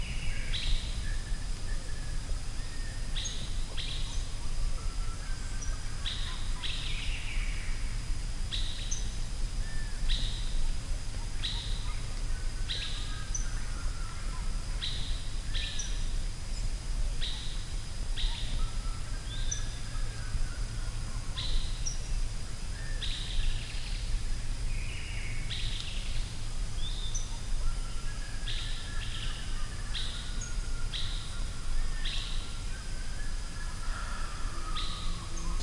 Brazil selva sounds " Mineiros Brasil selva 30.11.17 evening
描述：晚上在巴西塞尔瓦的鸟类和昆虫的声音 雨季的开始。
Tag: 鸟类 昆虫 性质 现场录音 晚上 巴西